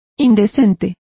Complete with pronunciation of the translation of obscene.